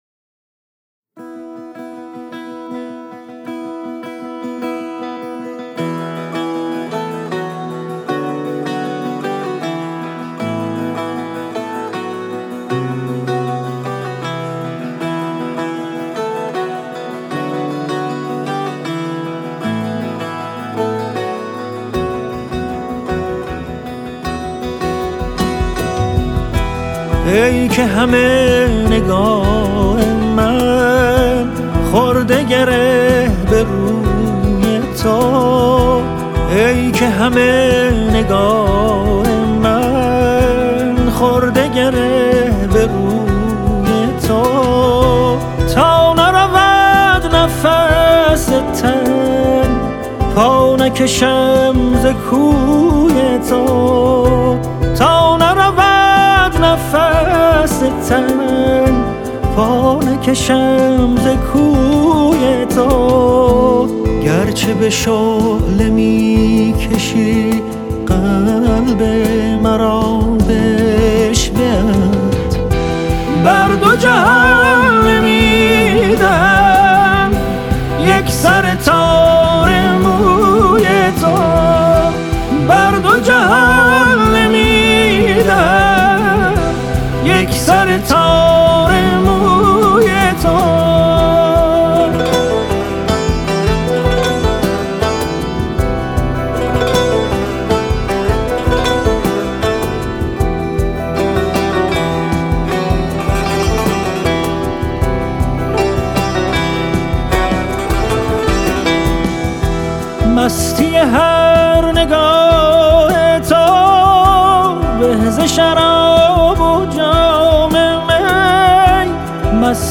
سنتی